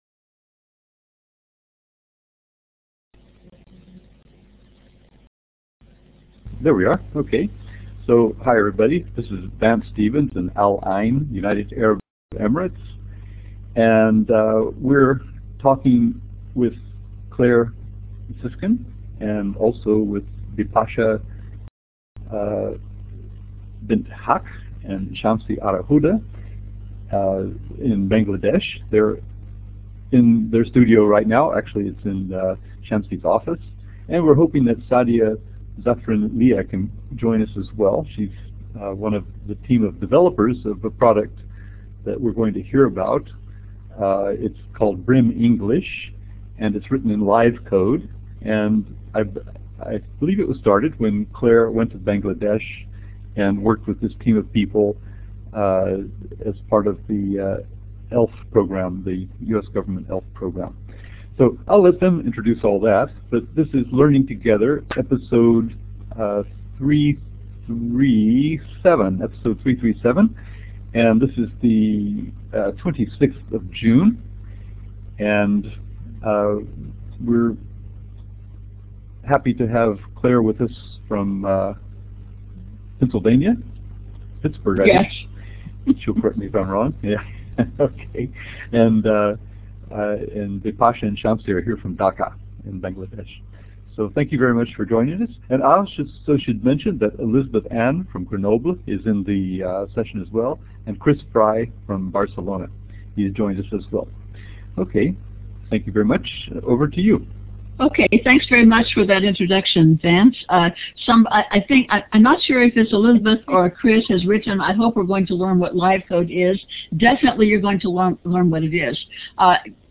Sun 26 Jun 2000 UTC Global Education Day streamed live from ISTE Denver